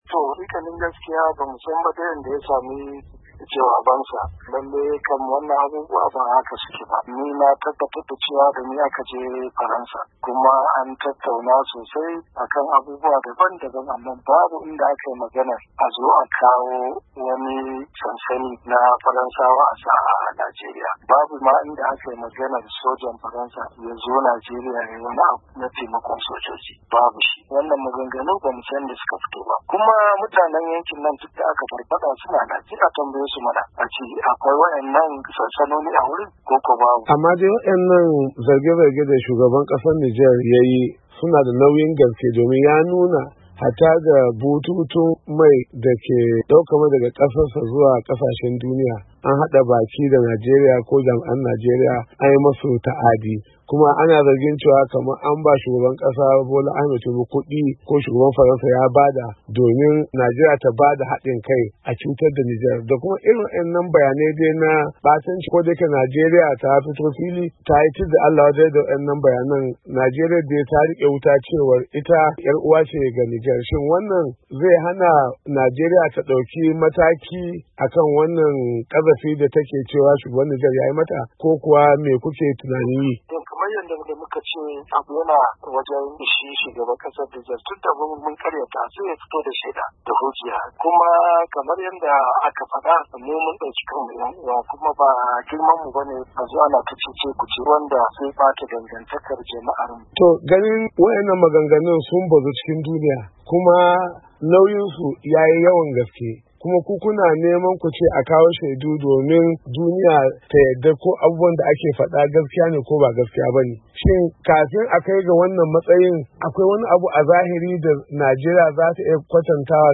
Hirar Babban Ministan Tsaro Kan Zargin Ta'addancin Da Nijar Ke Yi Wa Najeriya